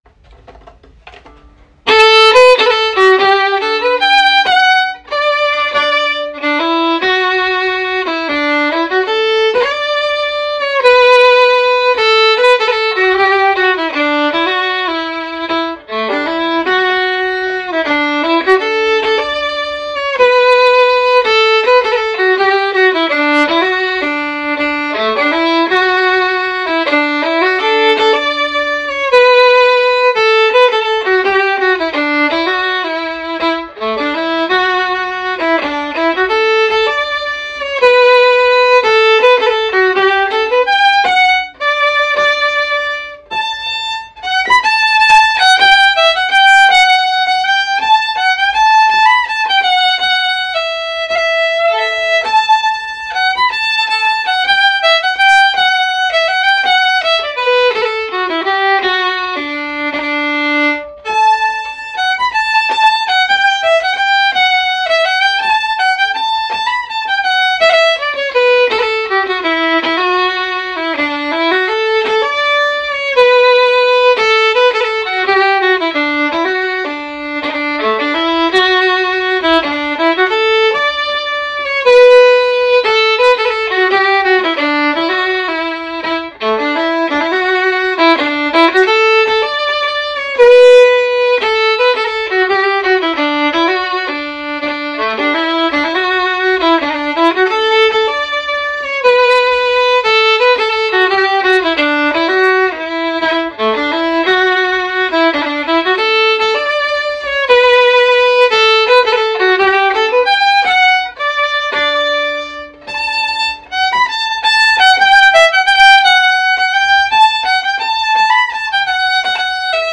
It promotes traditional Cape Breton style music through fiddle, guitar, piano, singers, step dancers, and lovers of Cape Breton Fiddle Music.